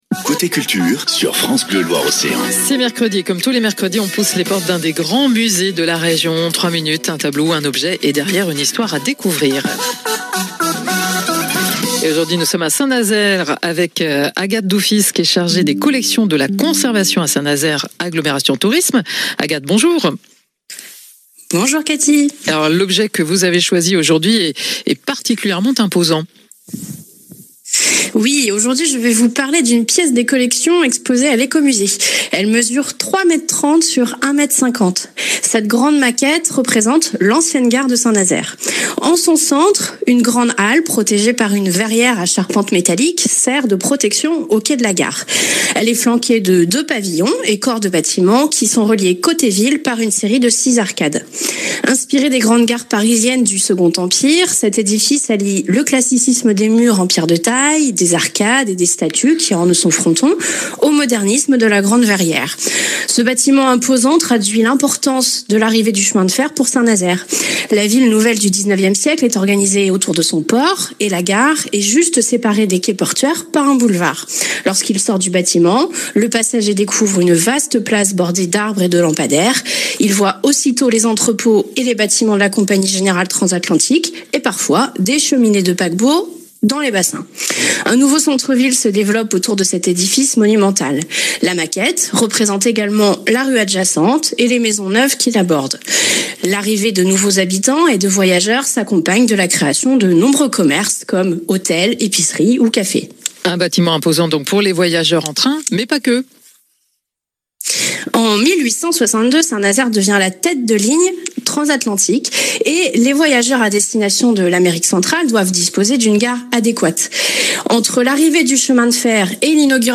Entretien